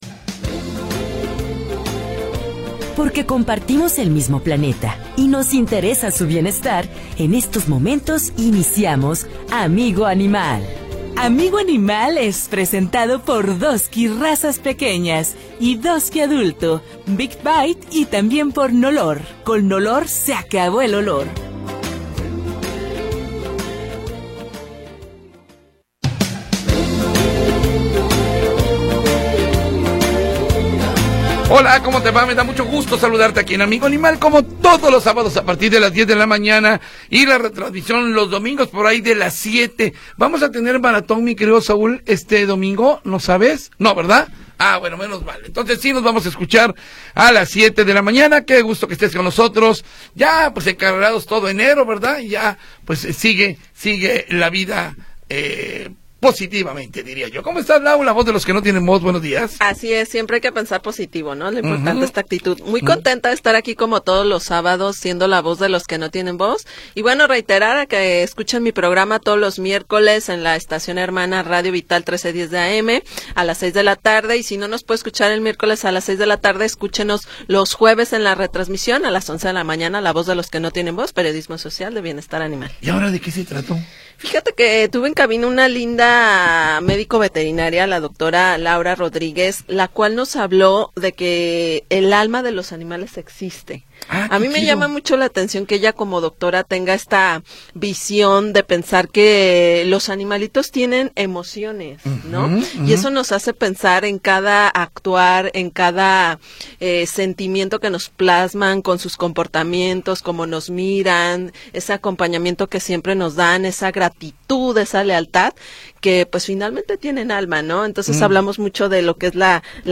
1 Metrópoli al Día 2da Hora - 17 de Enero de 2025 44:37 Play Pause 17h ago 44:37 Play Pause Прослушать позже Прослушать позже Списки Нравится Нравится 44:37 La historia de las últimas horas y la información del momento. Análisis, comentarios y entrevistas